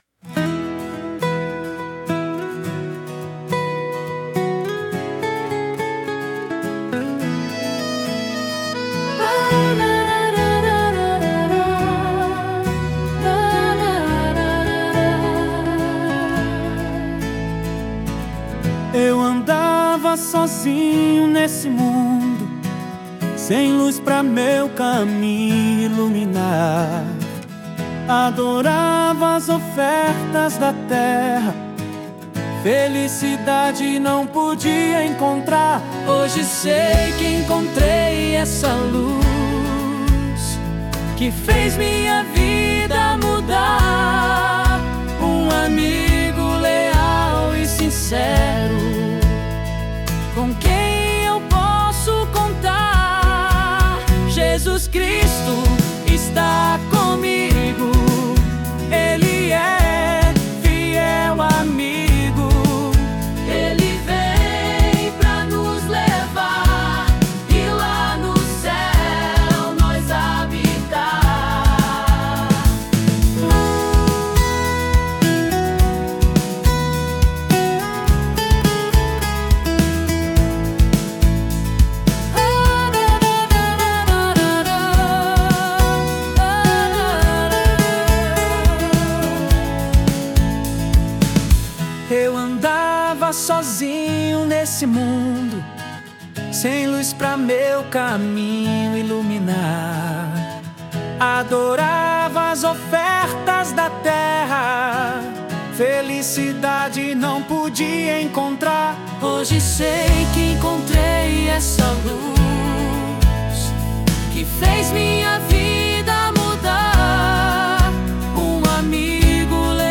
Transforme qualquer ideia em uma música incrível com voz, instrumentos de forma automática
[Vocal Masculino]